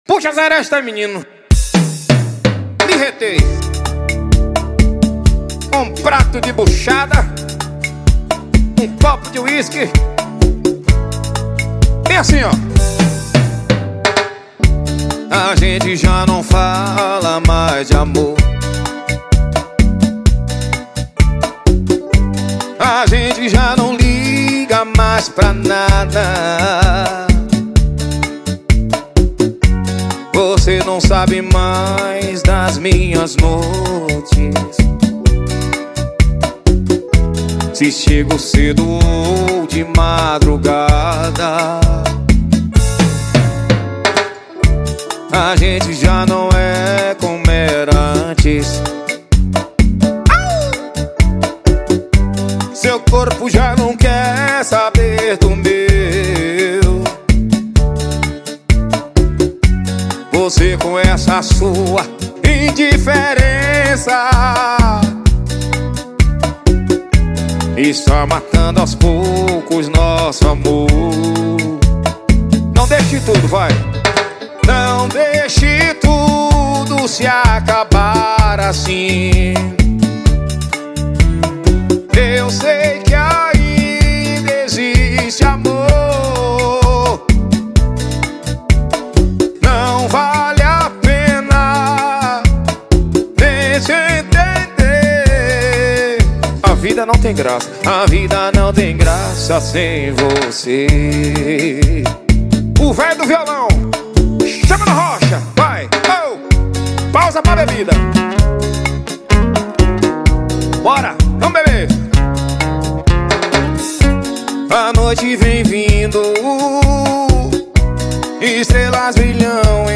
POUT-POURRIR  EM RITIMO DE SERESTA
VALE PENA CURTIR ESSE ROMANTISMO